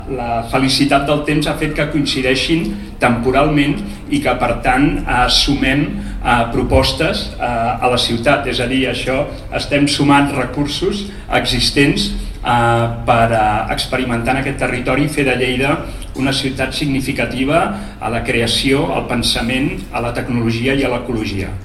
tall-de-veu-del-regidor-jaume-rutllant-sobre-el-nou-cicle-expositiu-de-la-panera